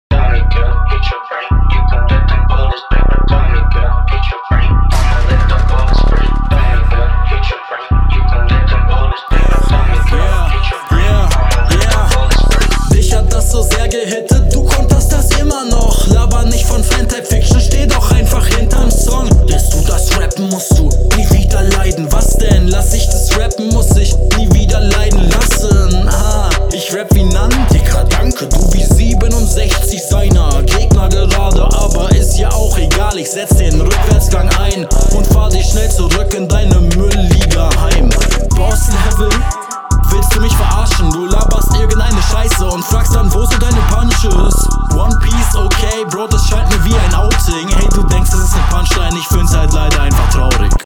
Flow nice, Mix mid, sehr gut gekontert, Reime sauber